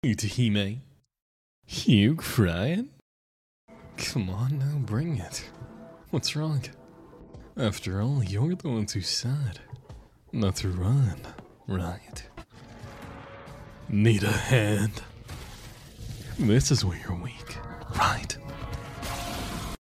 We also blew up on tiktok thanks to his really good Gojo impression, I was really impressed.